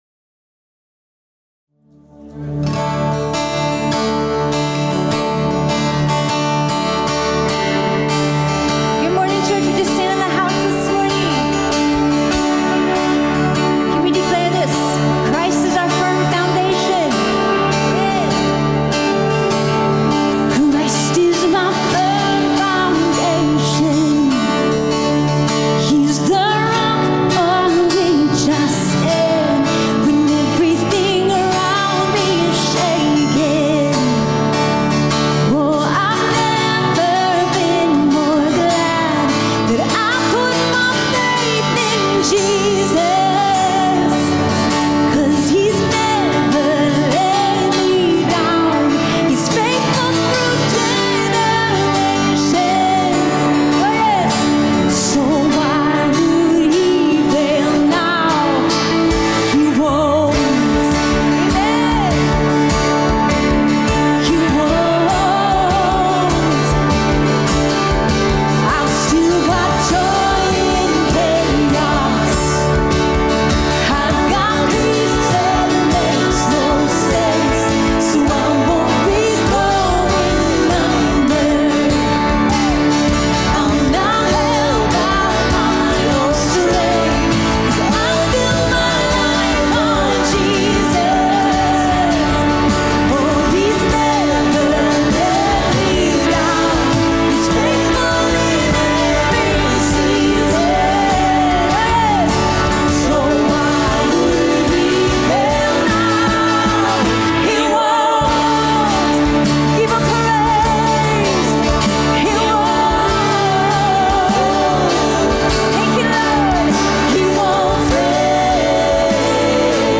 Topic: Show on Home Page, Sunday Sermons